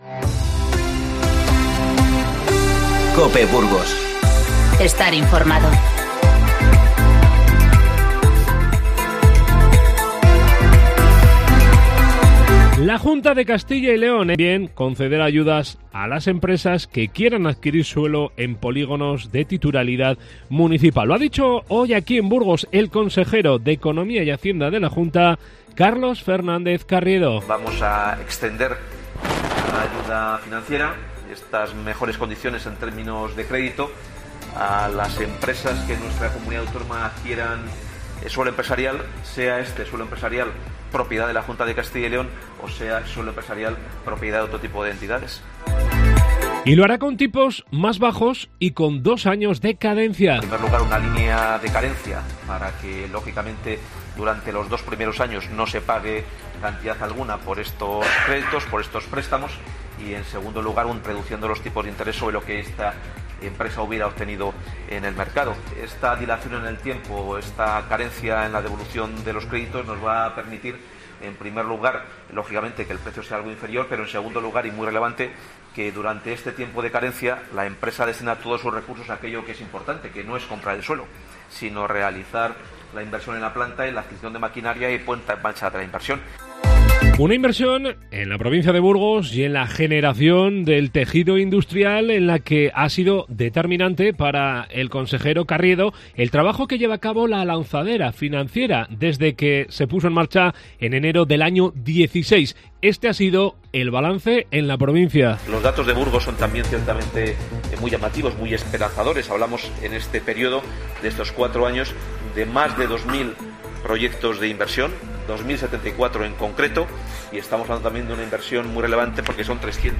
Informativo 24-01-20